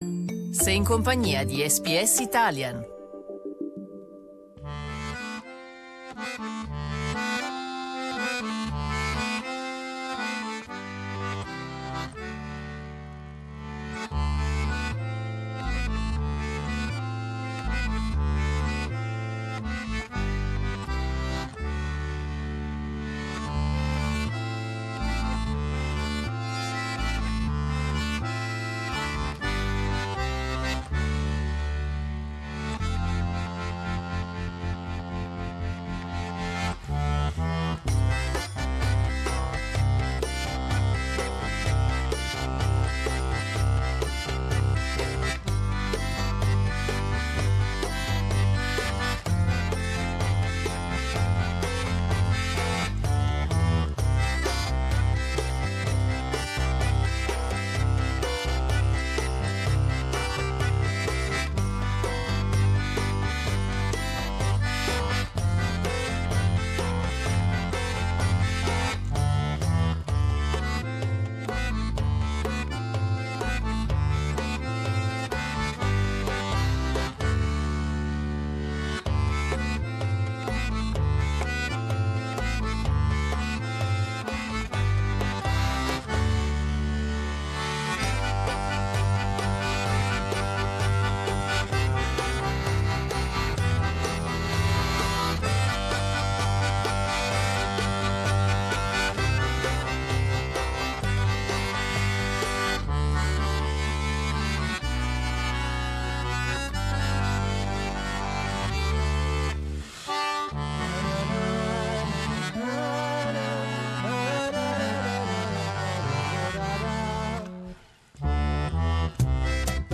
Sicilian trio